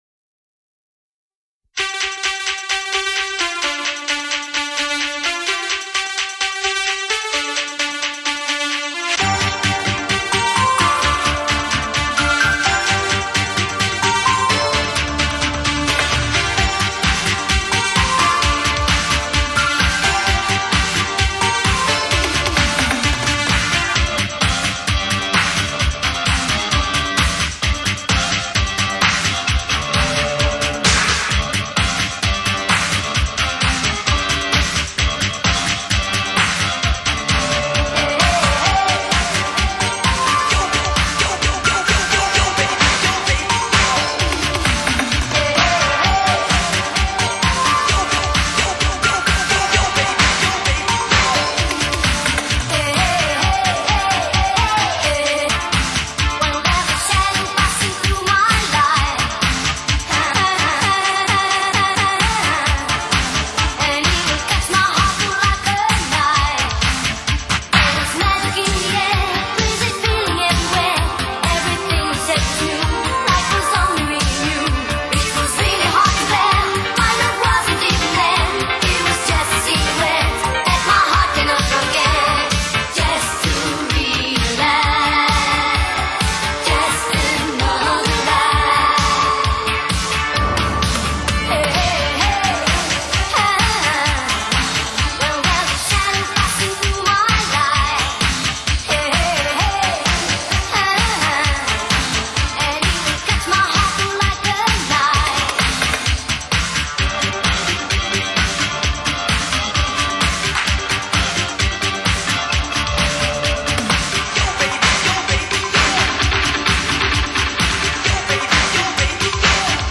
介绍： Italo disco